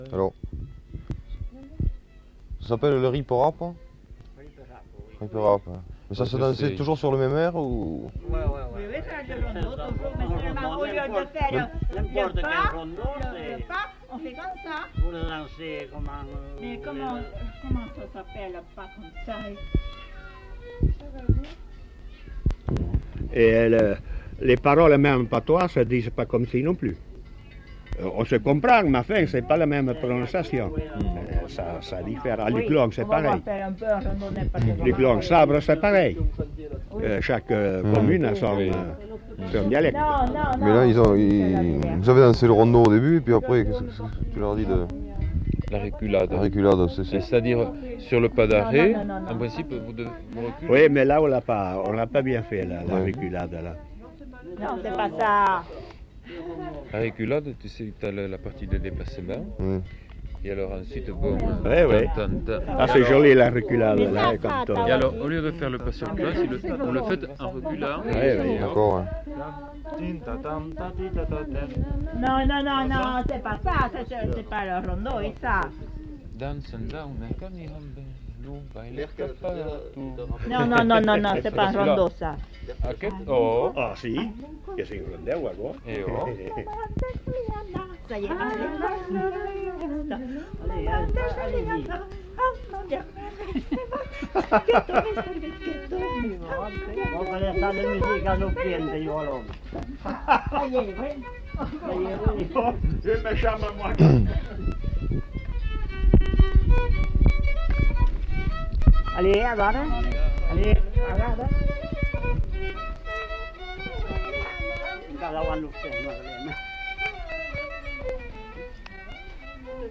Lieu : Labrit
Genre : témoignage thématique